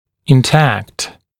[ɪn’tækt][ин’тэкт]интактный, здоровый; незараженный, неосложненный; неповрежденный
intact.mp3